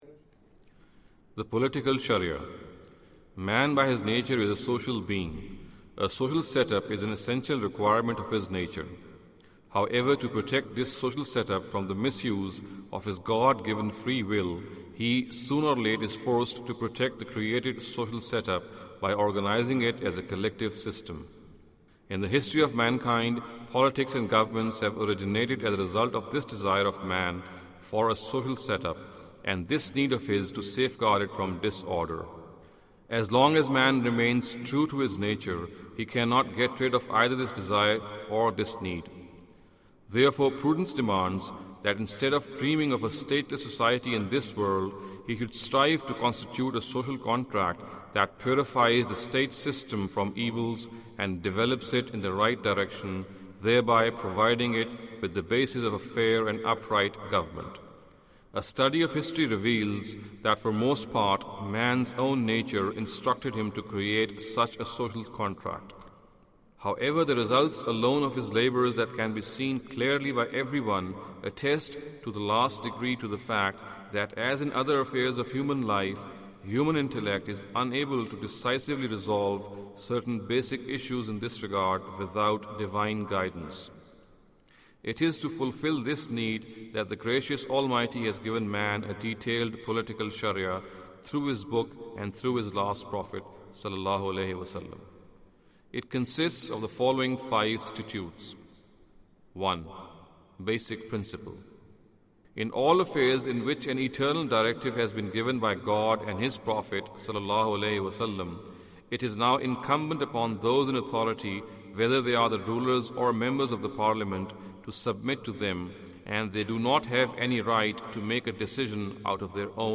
Audio book of English translation of Javed Ahmad Ghamidi's book "Islam a Concise Intro".